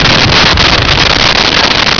Sfx Crash Metal Boomy
sfx_crash_metal_boomy.wav